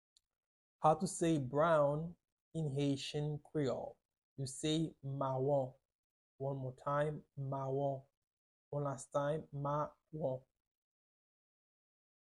Listen to and watch “mawon” audio pronunciation in Haitian Creole by a native Haitian  in the video below:
4.How-to-say-Brown-in-Haitian-Creole-mawon-with-Pronunciation.mp3